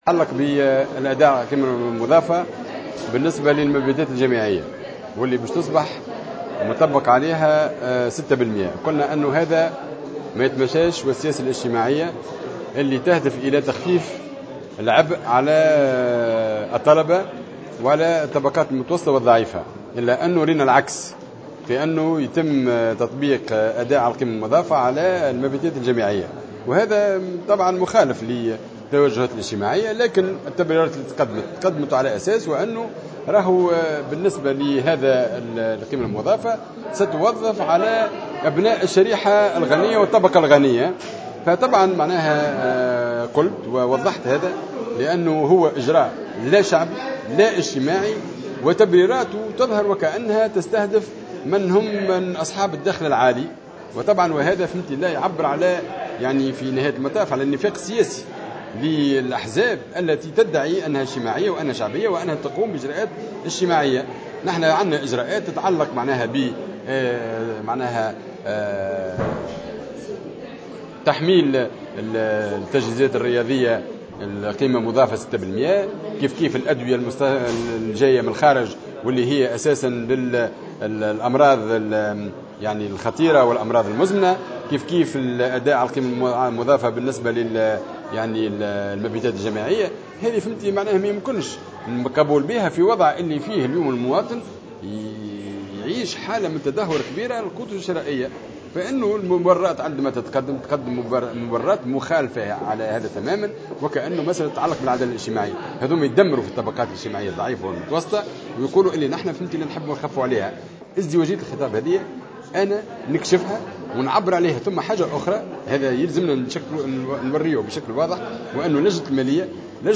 وأكد الرحوي (الجبهة الشعبية) في تصريحات صحفية على خلفية نقاش حاد جد اليوم في اجتماع للجنة المالية مع رجل الأعمال والنائب منصف السلامي (نداء تونس)، أن البرلمان أصبح ساحة لخدمة مصالح طبقيّة ورأسمالية على حساب انتظارات باقي الشعب التونسي.